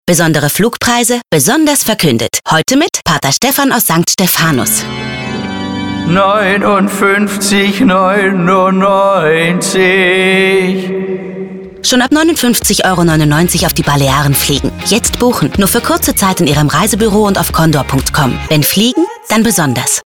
Radio spots: